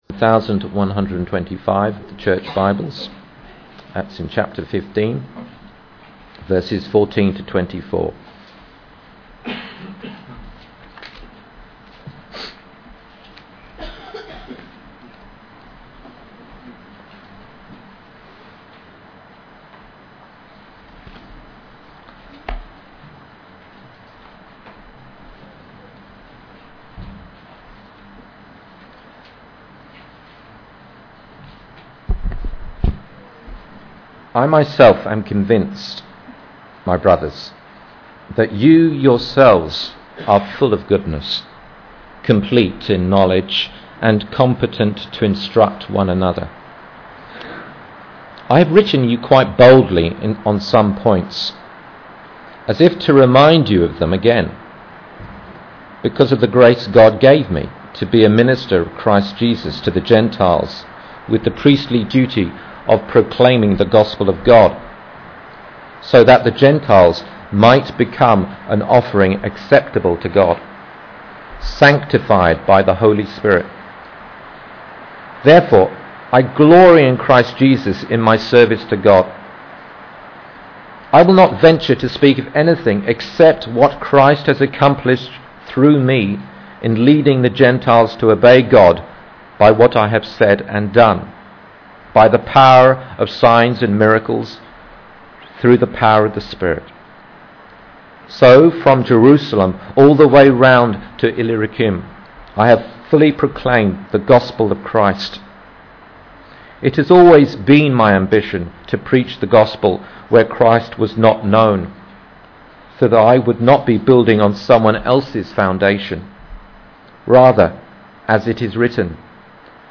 Romans 15:14-24 Service Type: Sunday AM Bible Text